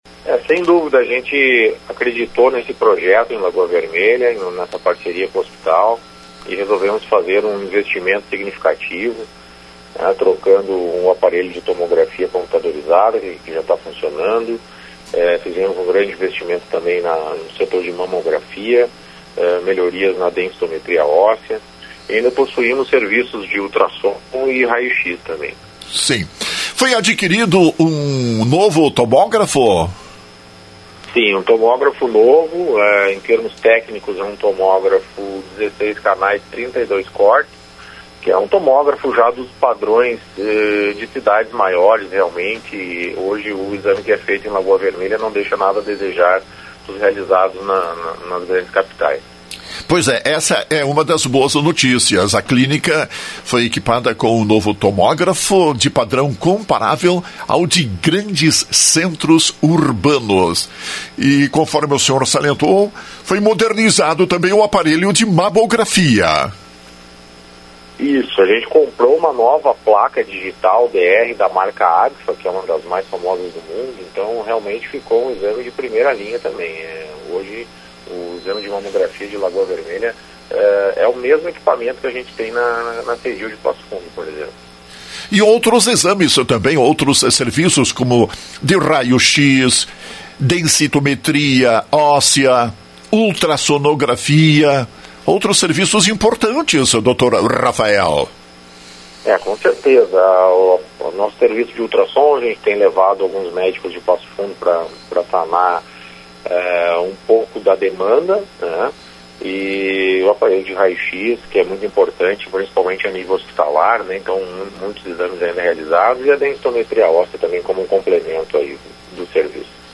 Na manhã desta quinta-feira, Rádio Lagoa FM entrevistou